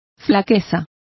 Complete with pronunciation of the translation of infirmity.